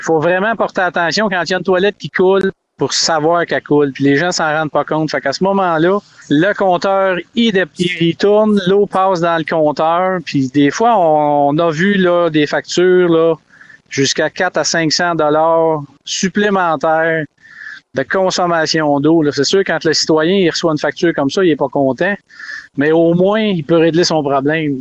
Celui qui était maire jusqu’à vendredi dernier avant de tirer sa révérence après trois mandants, Claude Lefebvre, a mentionné que certains citoyens avaient parfois une mauvaise surprise en voyant leur facture.
Jeudi-–-Claude-Lefebvre.mp3